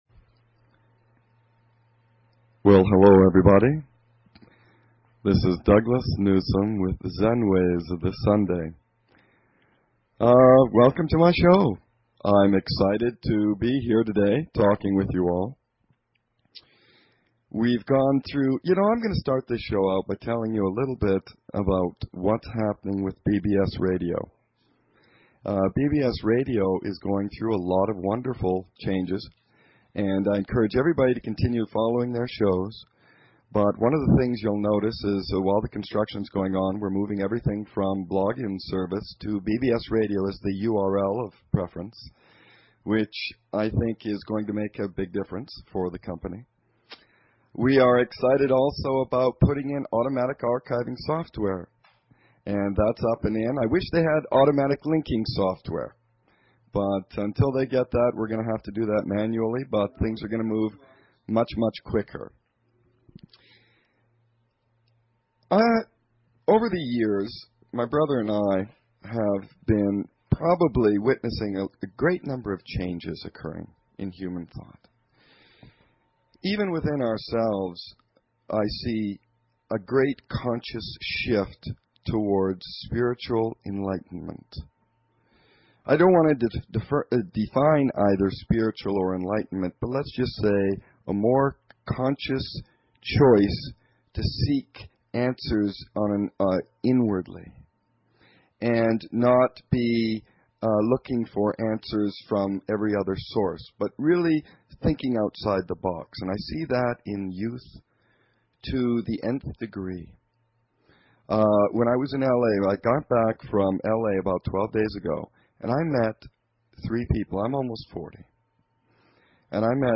Talk Show Episode, Audio Podcast, Zen_Ways and Courtesy of BBS Radio on , show guests , about , categorized as